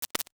NOTIFICATION_Subtle_10_mono.wav